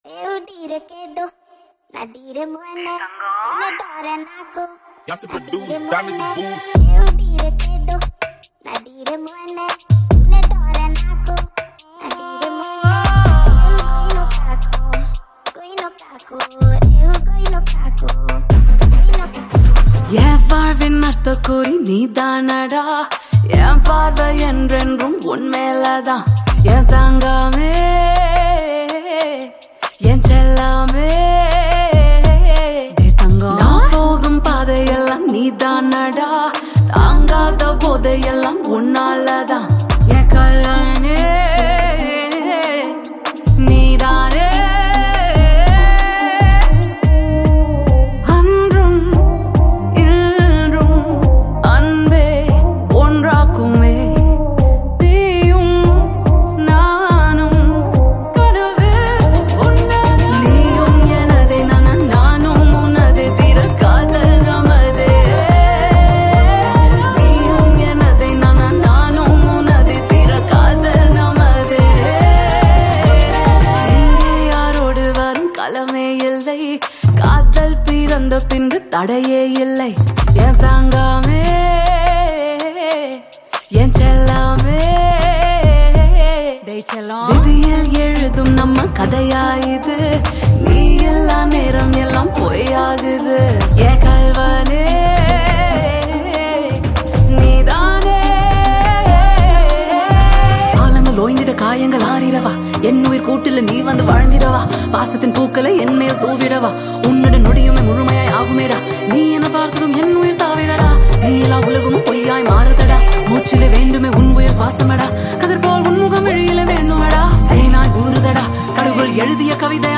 romantic indie Tamil song